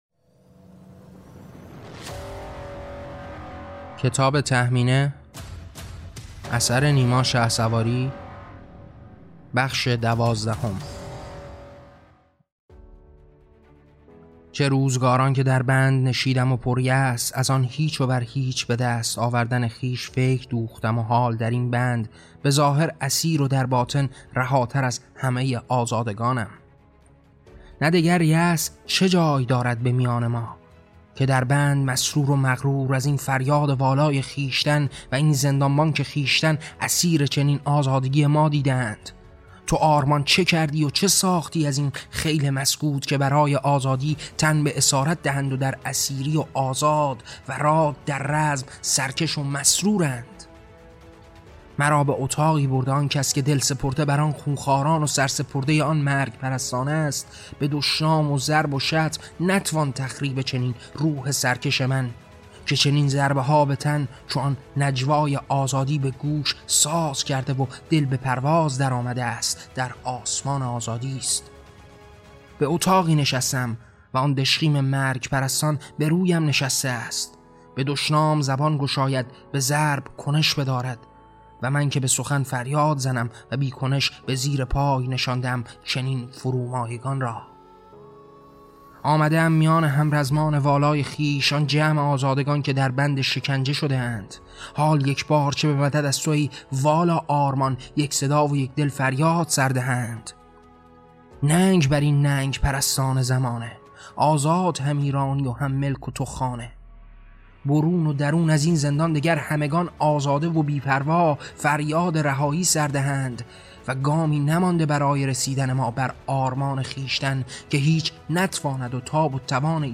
کتاب صوتی تهمینه - بخش دوازدهم قسمت پایانی